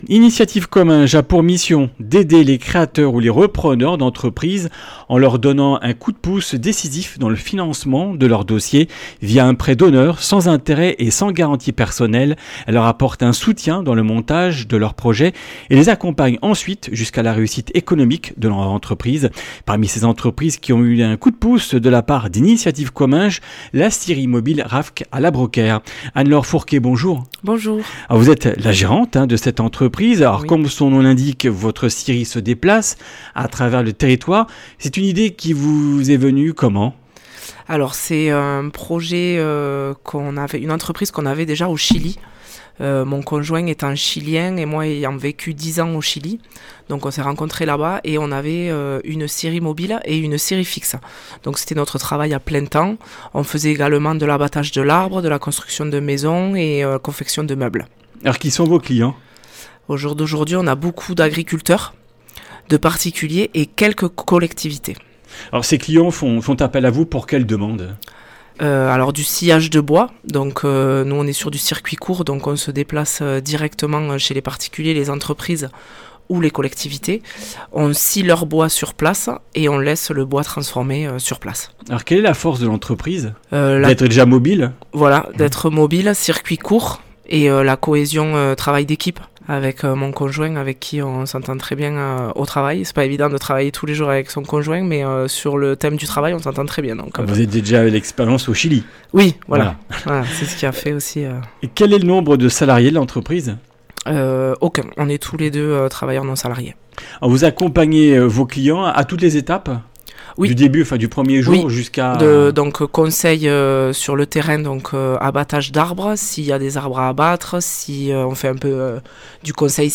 Comminges Interviews du 27 janv.
Une émission présentée par